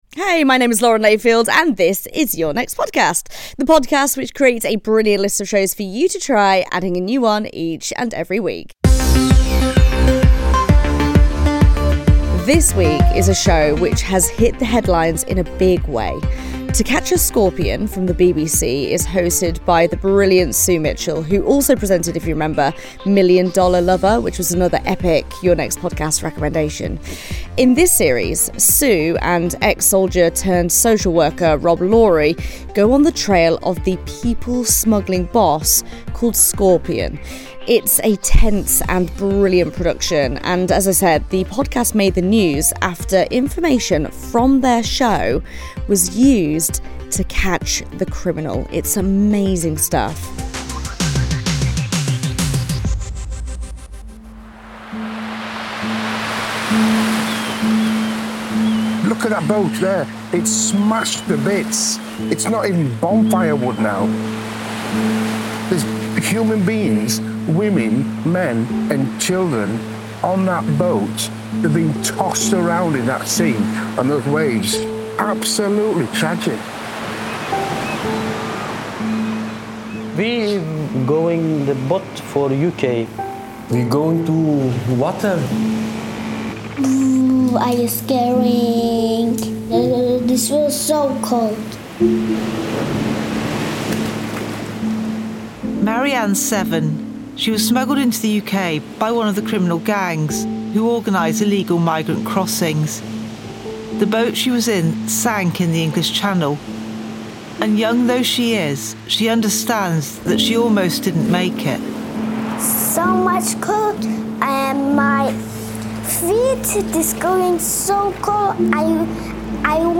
Lauren Layfield introduces How to Catch A Scorpion on the podcast recommendation podcast - Your Next Podcast.
To Catch A Scorpion: The dramatic hunt for one of Europe's most-wanted people smugglers, recorded as it unfolds.